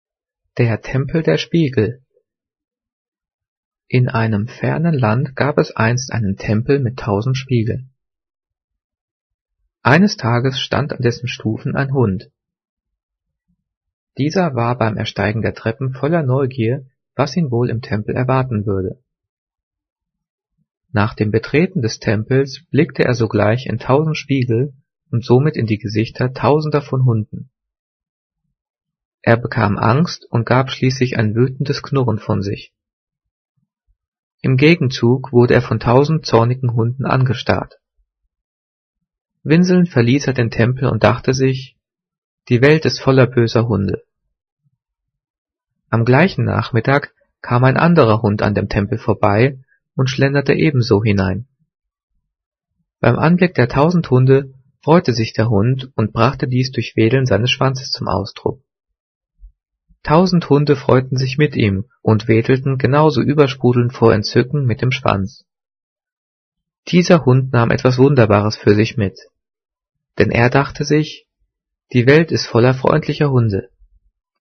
Gelesen:
gelesen-der-tempel-der-spiegel.mp3